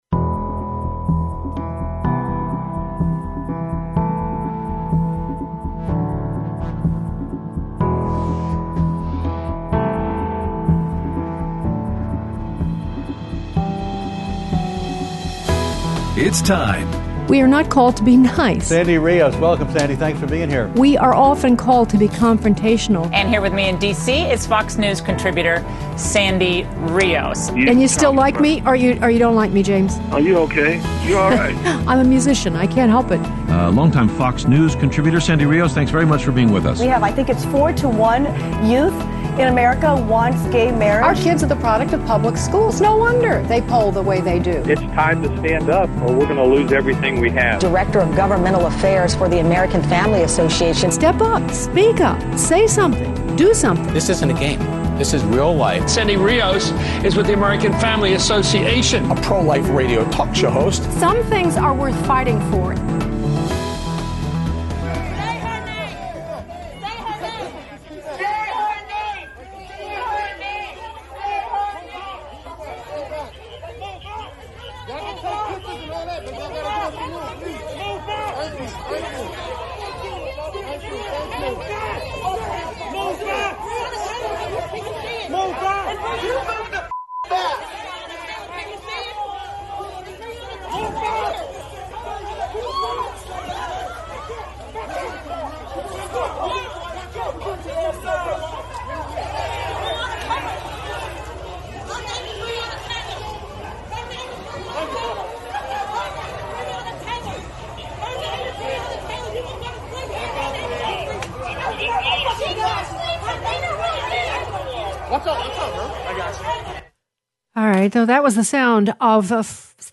Interview with Lt. Gen. (Ret.) Jerry Boykin about Stand Courageous Conferences and His Book Man to Man
Aired Monday 8/31/20 on AFR 7:05AM - 8:00AM CST